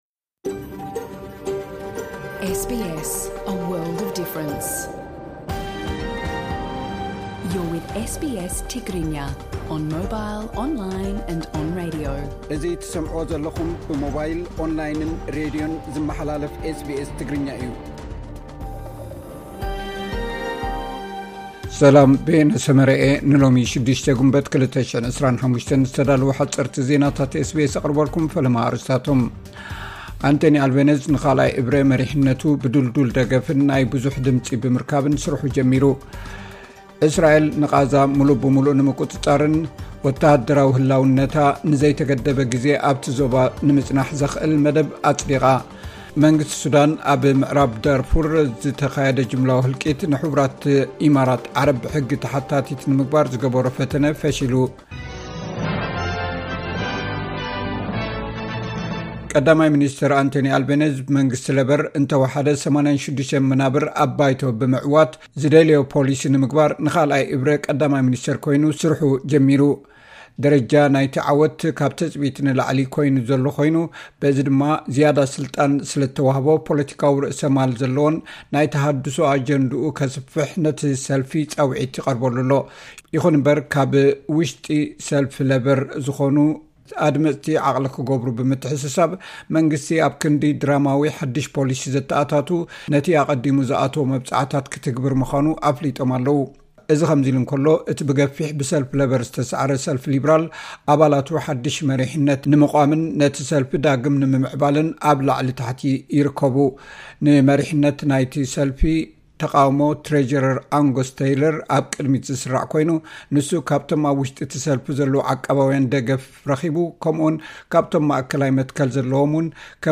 ሓጸርቲ ዜናታት ኤስ ቢ ኤስ ትግርኛ (06 ግንቦት 2025)